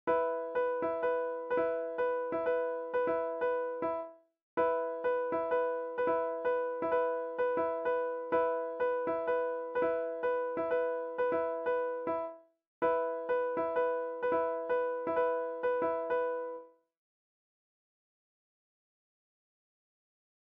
543. 8:5 - 11:7 AproPo